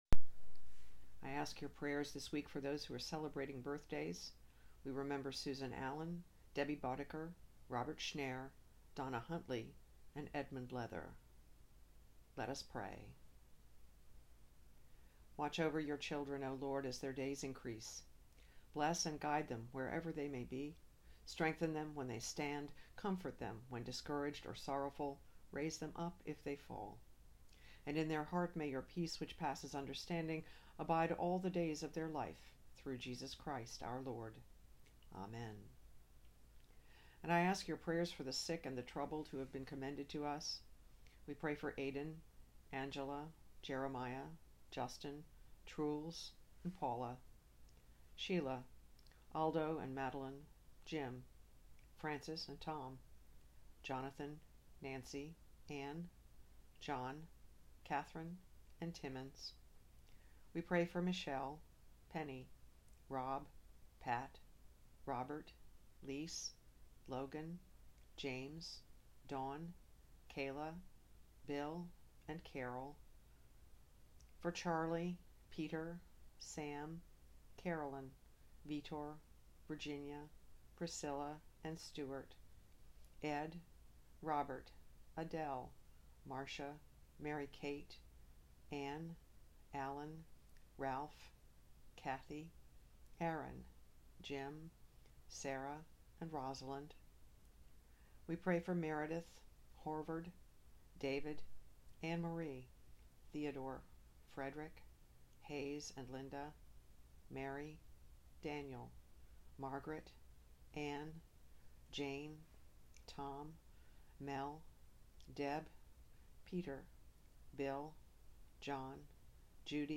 Prayers and Sermon